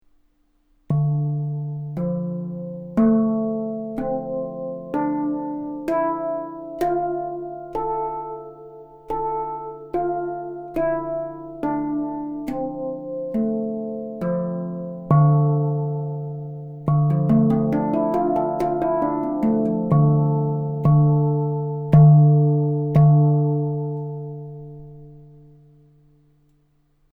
Découvrez la magie du Hang Drum Atlantic'O | 8 Notes (Spacedrum Evolution) - Un instrument de musique en acier inoxydable de haute qualité avec 8 notes harmonieuses.
BAGUETTES / BAGUETTES